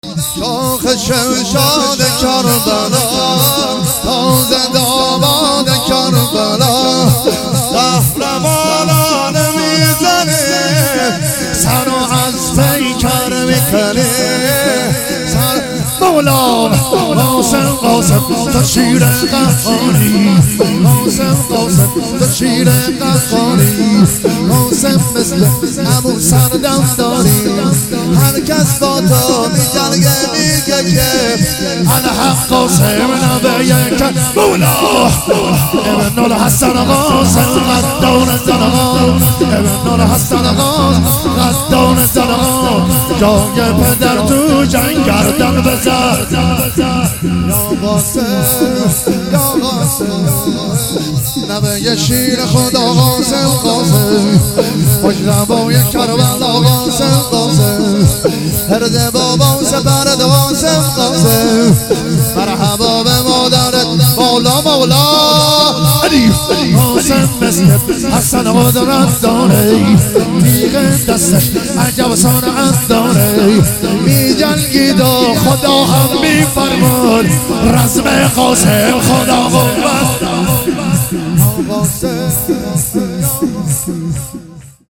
ایام فاطمیه 1399